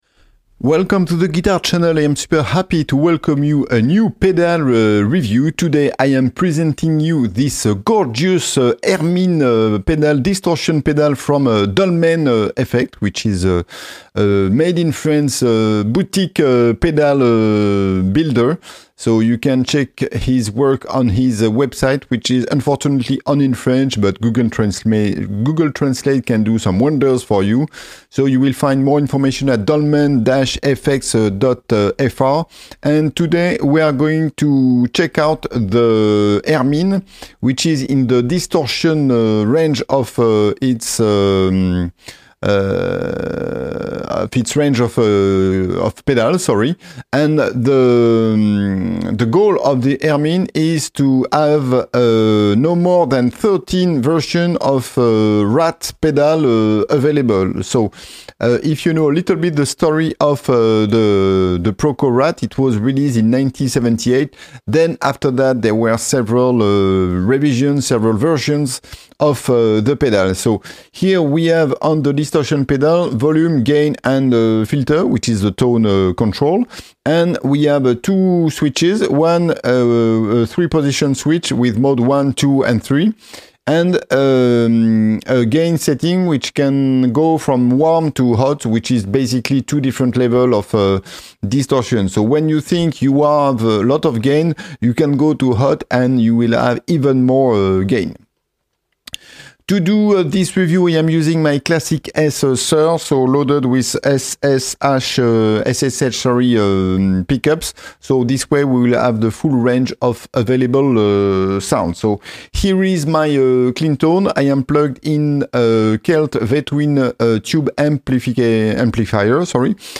Hermine Dolmen Effects pedal review